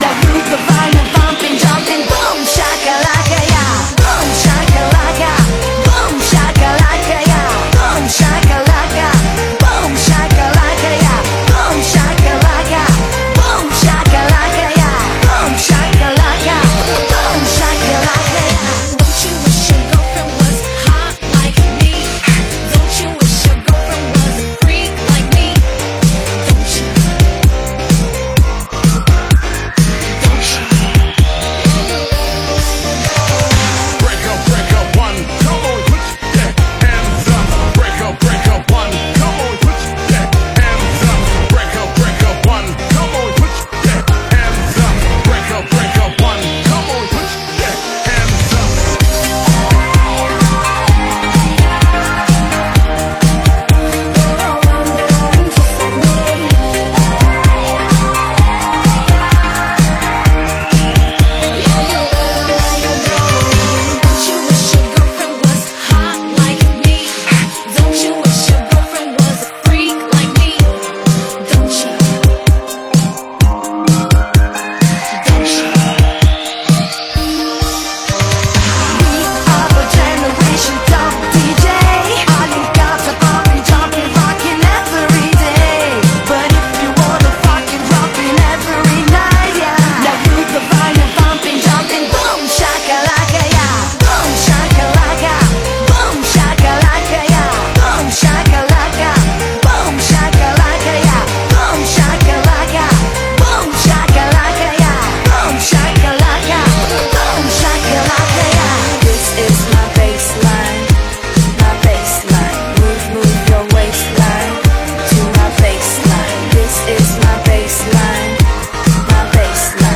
本铃声大小为848KB，总时长137秒，属于DJ分类。